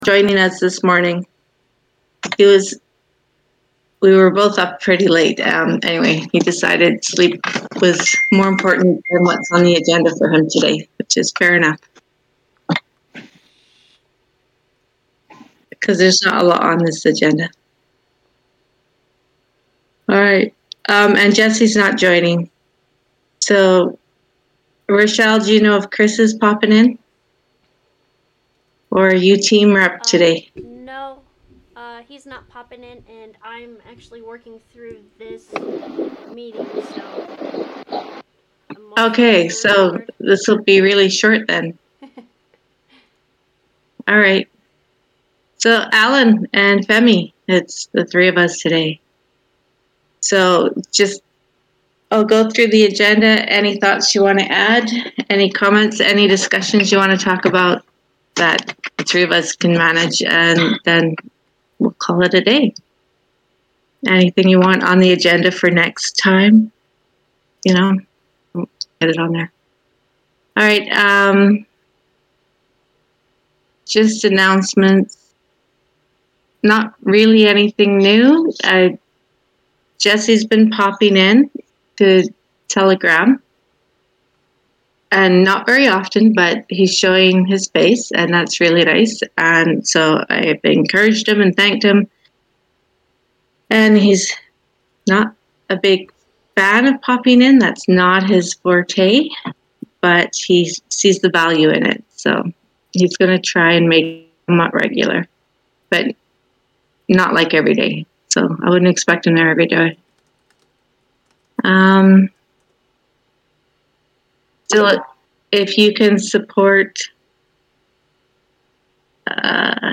DAO Call. incomplete